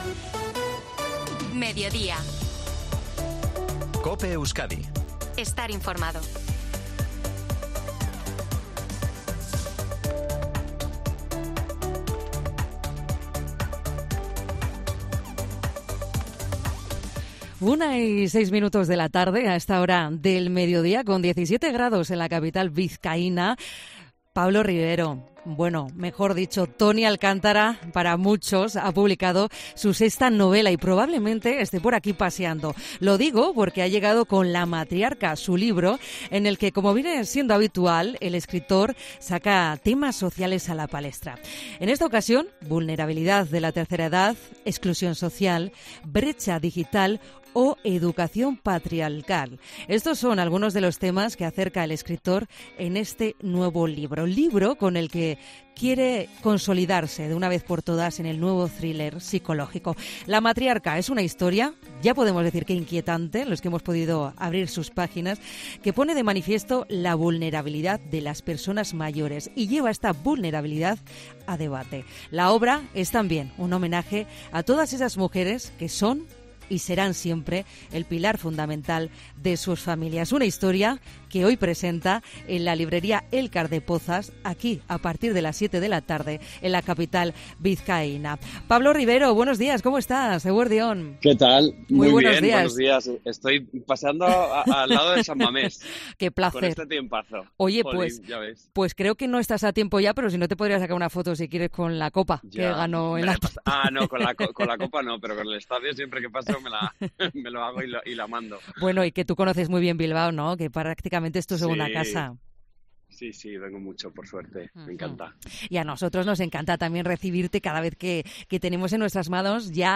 El actor y escritor se pasa por los micrófonos de COPE Euskadi para adelantar los detalles de su sexto libro con el que llega a la librería Elkar...